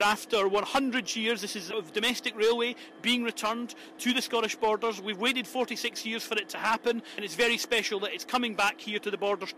Scottish Borders Council Leader David Parker says it's a momentous day.